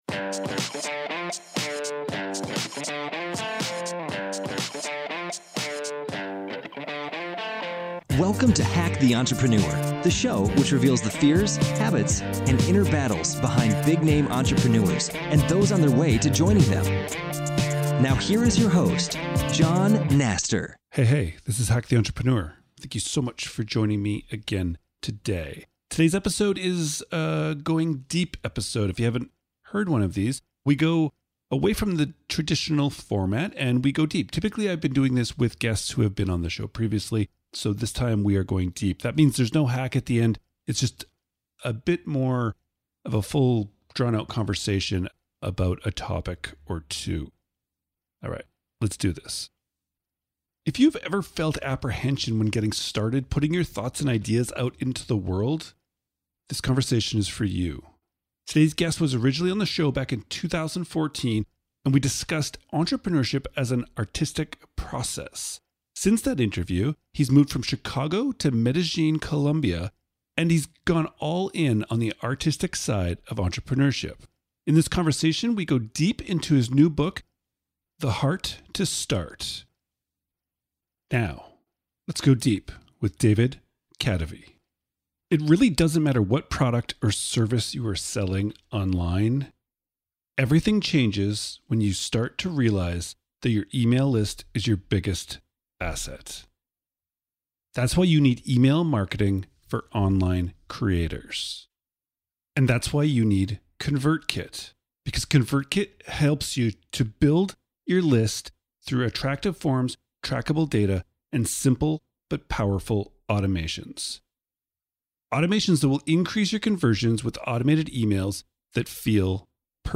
If you ever felt apprehension when getting started putting your thoughts and ideas out into the world, then this conversation is for you.